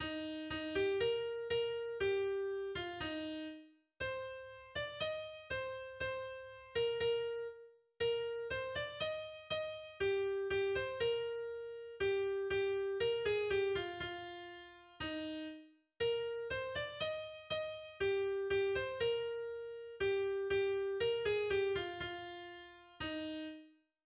Volkslied aus dem frühen 19. Jahrhundert